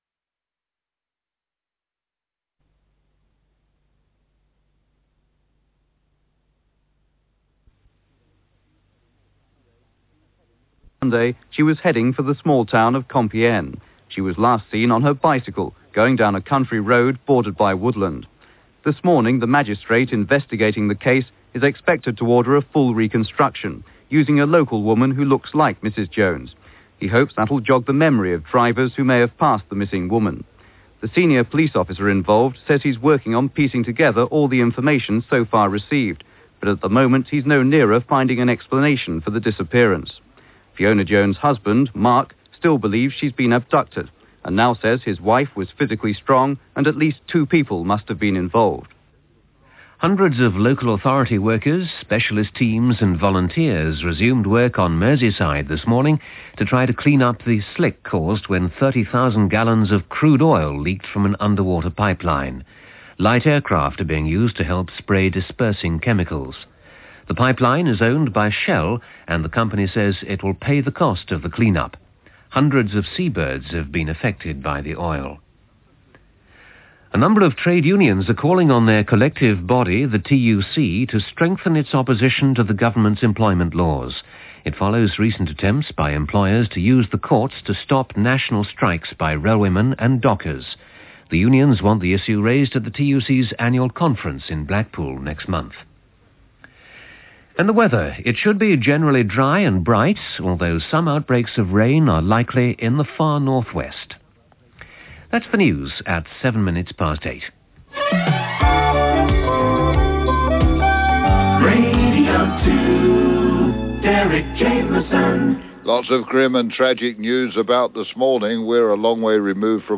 Link to the the Radio Show (Please Note: The audio starts at 10 seconds)
Radio 2 OB Elham Sept 1989.mp3